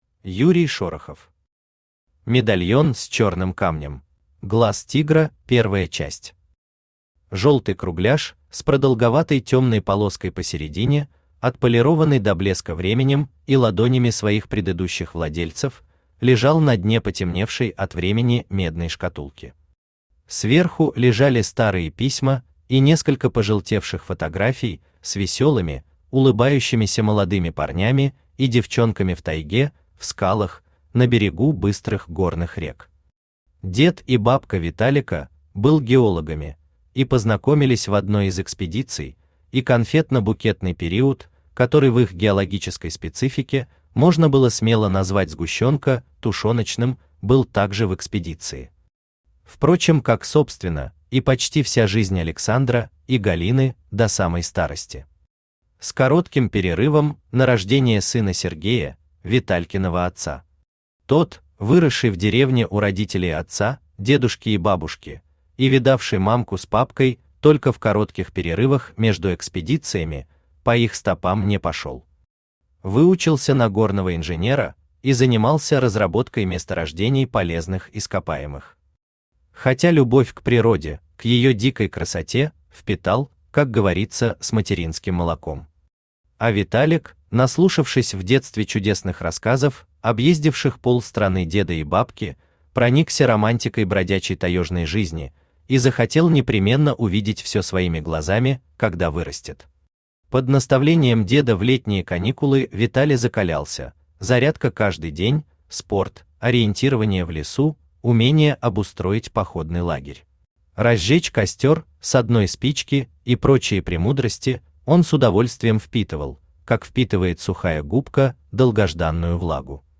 Аудиокнига Медальон с чёрным камнем | Библиотека аудиокниг
Aудиокнига Медальон с чёрным камнем Автор Юрий Шорохов Читает аудиокнигу Авточтец ЛитРес.